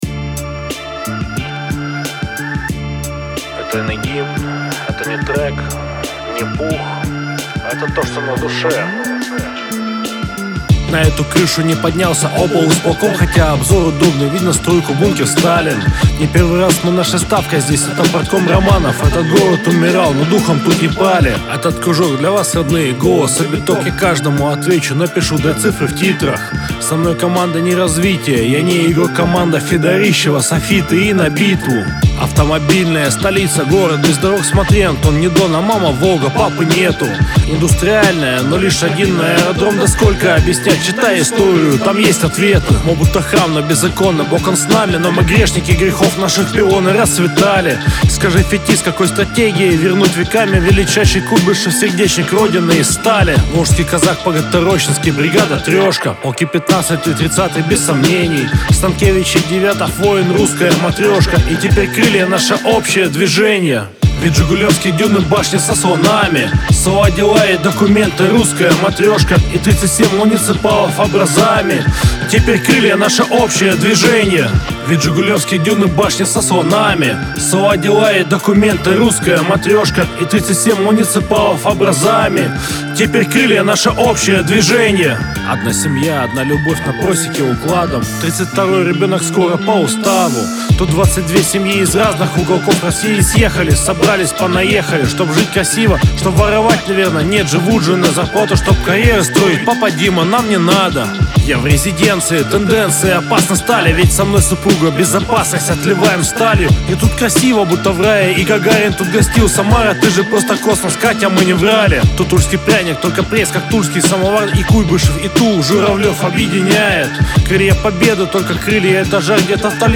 01 из 03 «Добрый» рэп